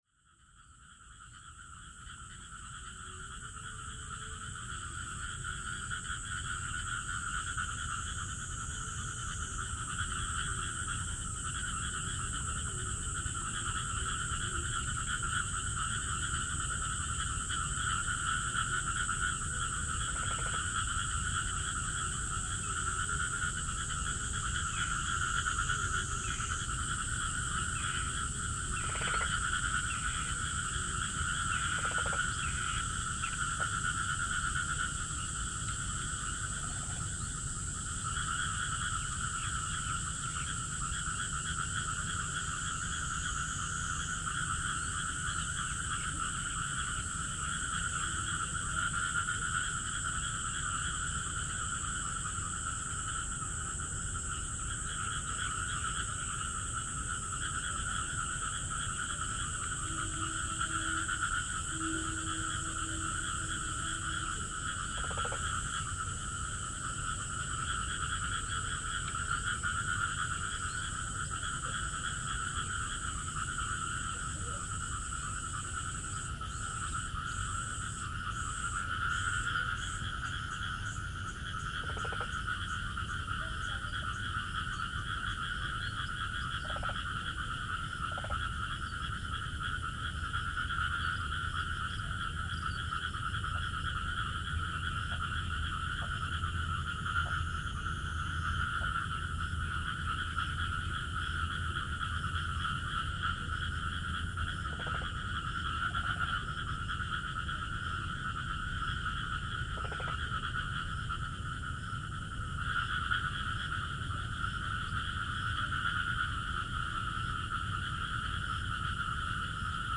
Morning in Qixia (Field Recording)
A field recording from Xuanwu Lake in Nanjing, China, July 2017 Audio 69
frogs-in-qixia-2017.mp3